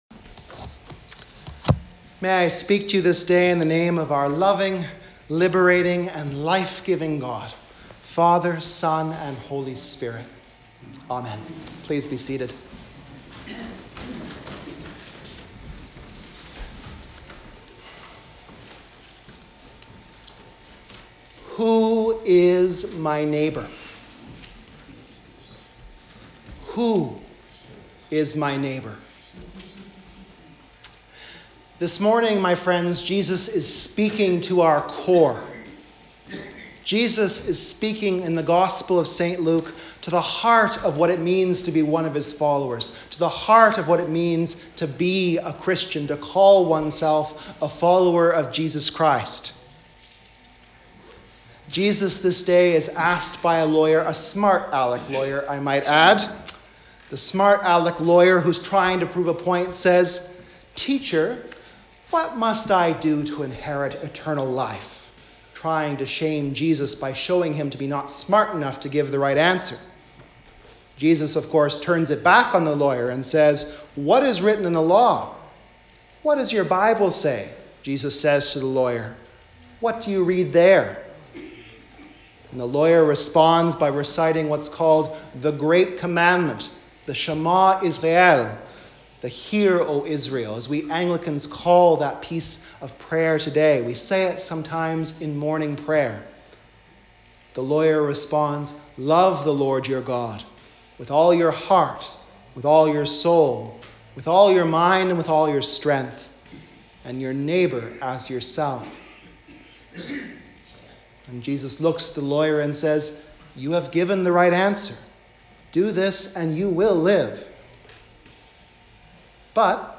Sermons | Parish of the Valley
Preached at St. George's South Alice and All Saints Petawawa.